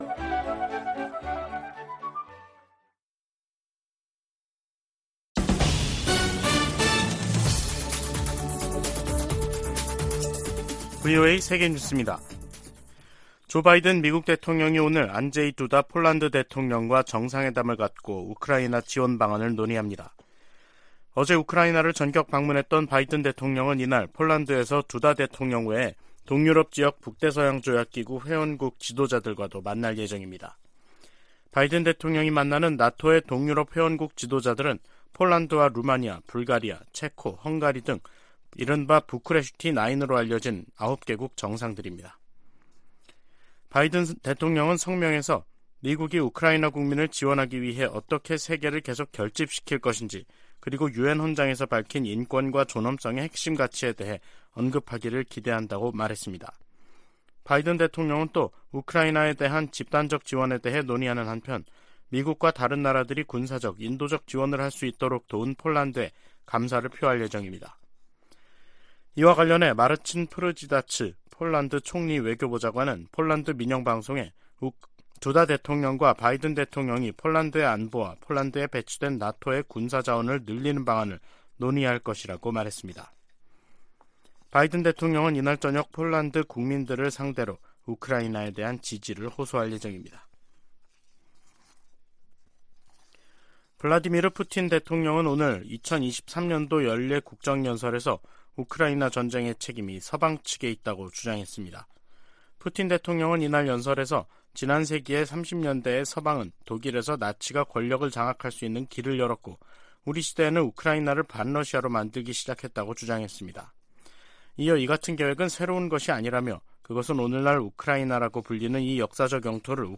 VOA 한국어 간판 뉴스 프로그램 '뉴스 투데이', 2023년 2월 21일 2부 방송입니다. 유엔 안전보장이사회가 북한의 대륙간탄도미사일(ICBM) 발사에 대응한 공개회의를 개최한 가운데 미국은 의장성명을 다시 추진하겠다고 밝혔습니다. 김여정 북한 노동당 부부장은 ‘화성-15형’의 기술적 문제점을 지적하는 한국 측 분석들을 조목조목 비난하면서 민감한 반응을 보였습니다. 북한이 ICBM급에도 대기권 재진입 기술을 확보했다는 관측이 확대되고 있습니다.